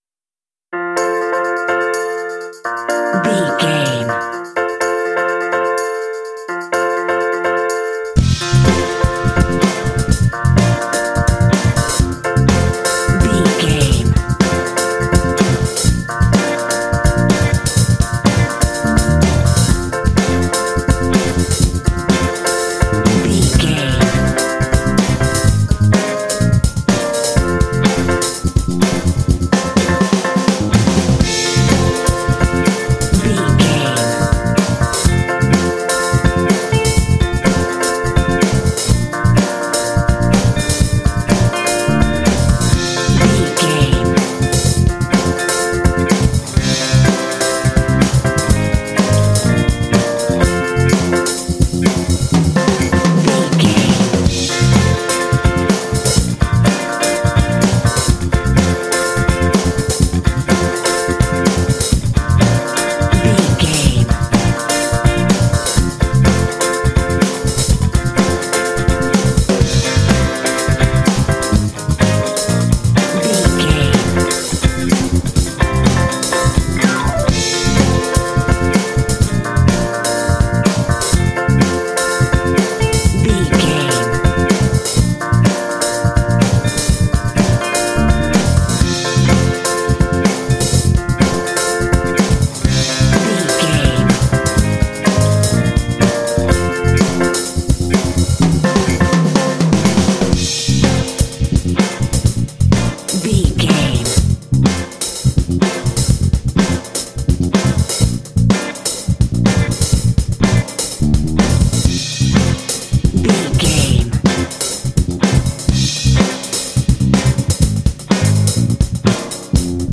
This funk track is reminiscent of 12-bar blues phrasing.
Uplifting
Ionian/Major
groovy
funky
driving
energetic
piano
bass guitar
electric guitar
drums
percussion